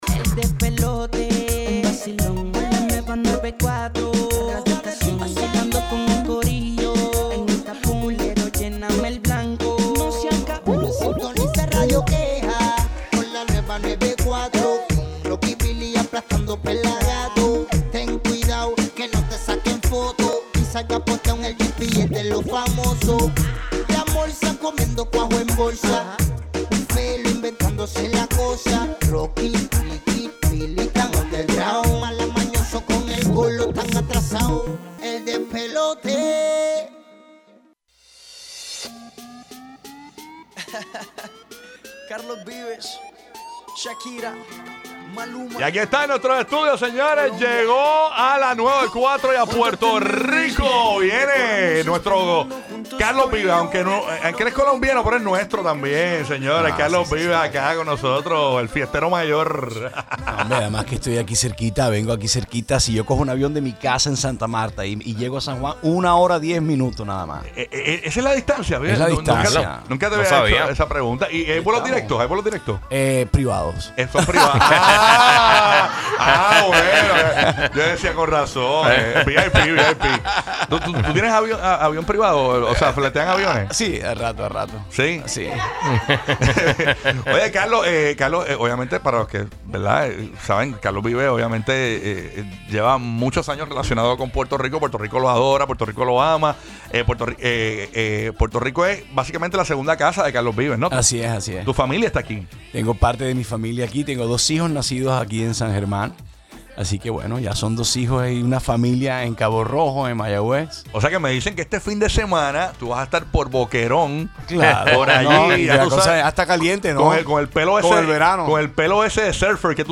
ENTREVISTA CARLOS VIVES - lanueva94
ENTREVISTA-CARLOS-VIVES-.mp3